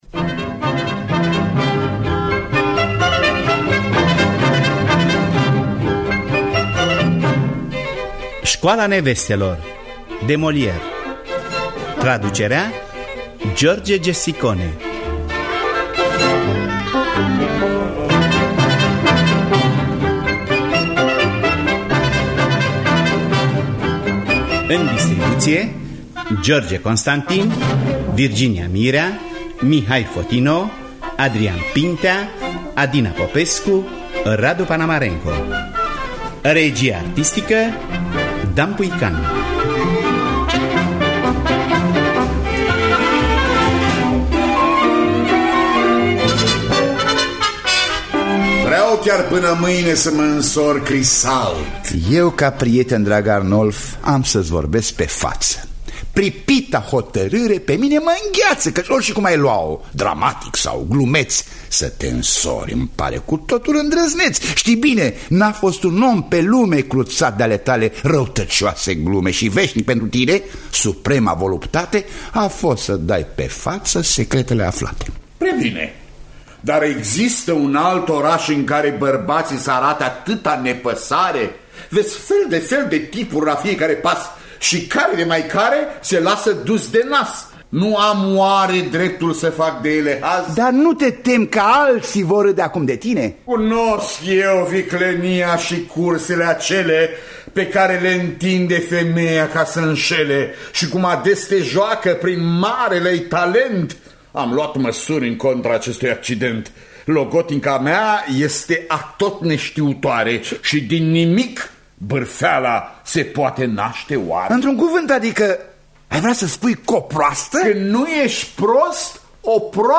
Teatru Radiofonic Online